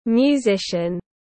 Musician /mjuˈzɪʃn/
Musician.mp3